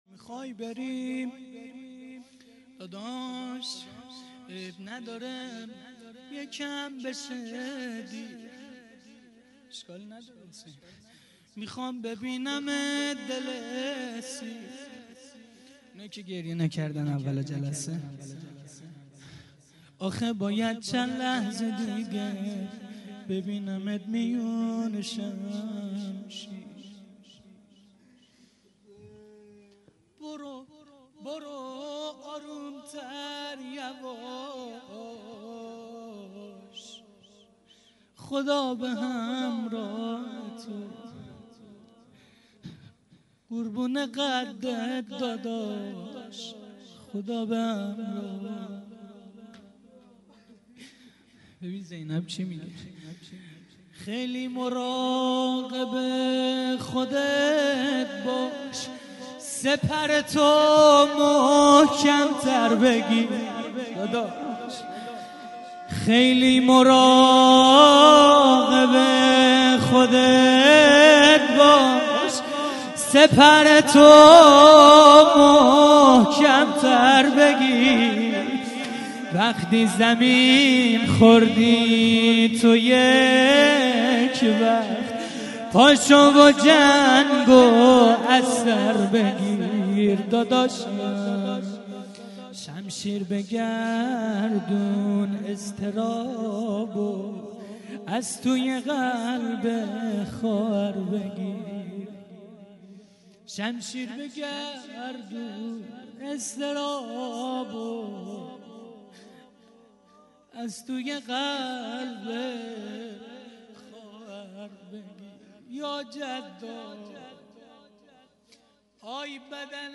روضه پایانی 06.mp3